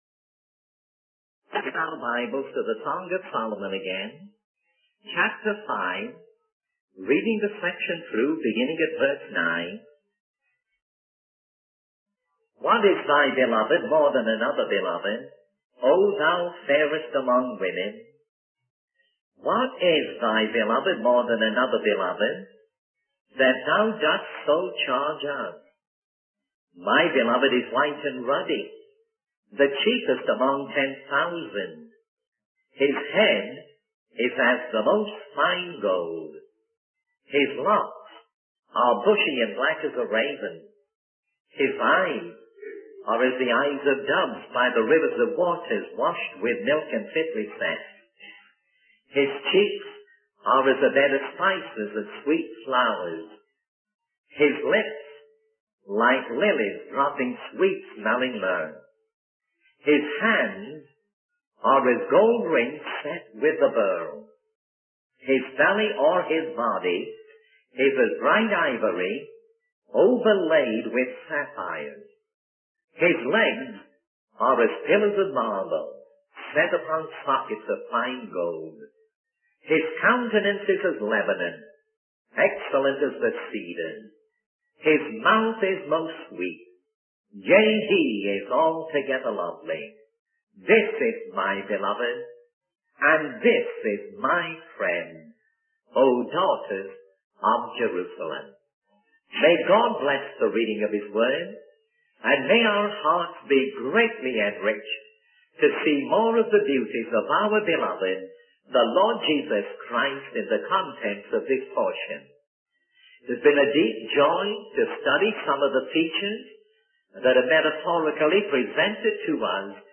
In this sermon, the preacher emphasizes that every doctrine and teaching should lead believers to Jesus Christ. The focus is on the hands of Jesus, described as being like gold rings set with precious stones.